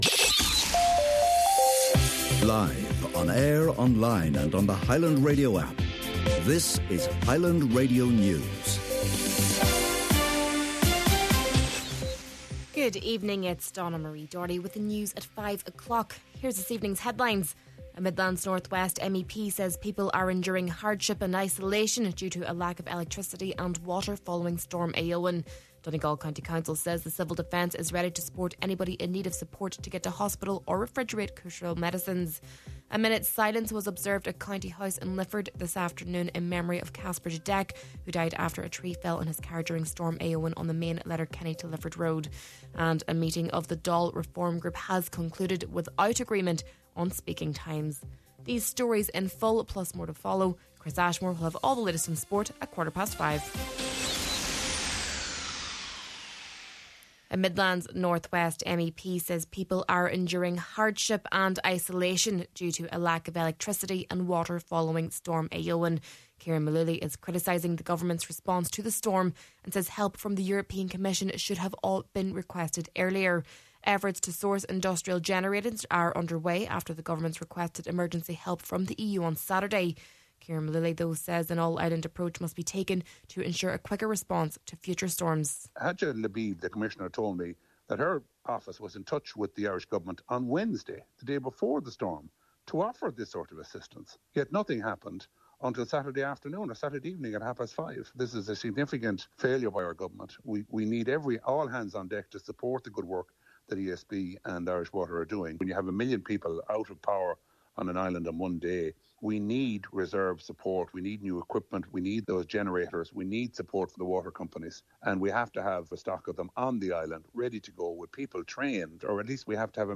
Main Evening News, Sport and Obituaries – Monday, January 27th